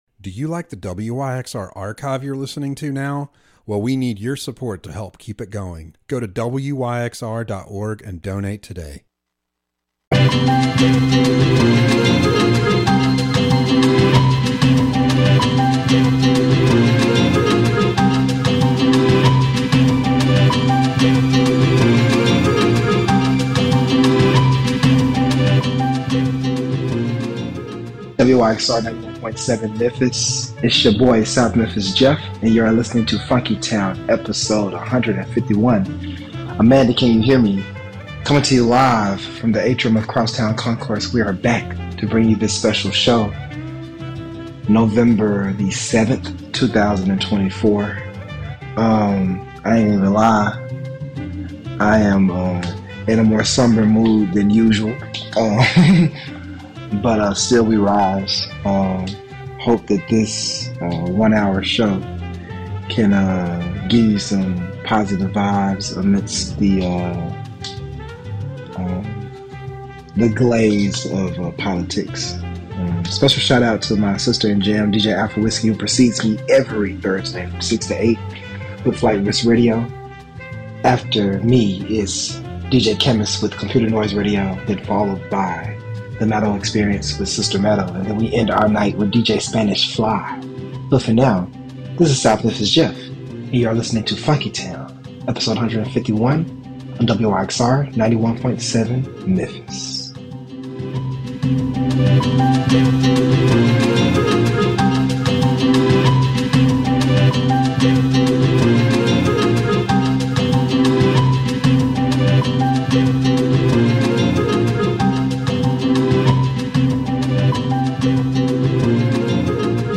Enjoy soul, funk, and hip-hop from the past and future.